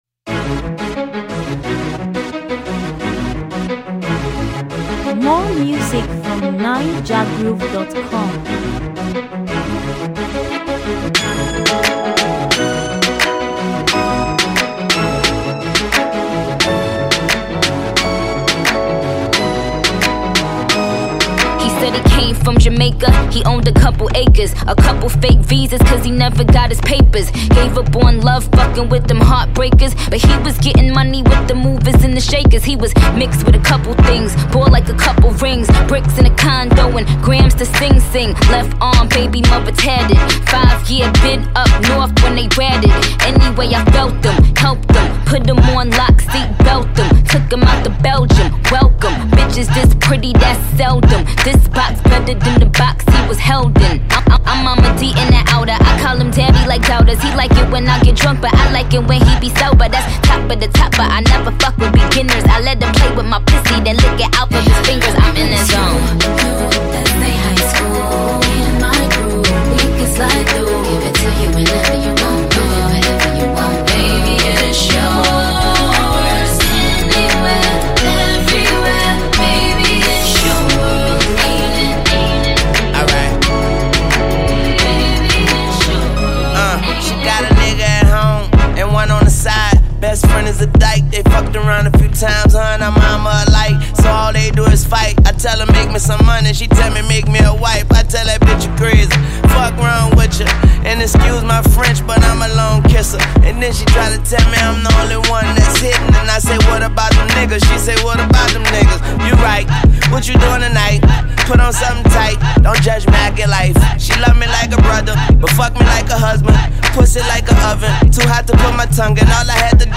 Hip-Hop, Latest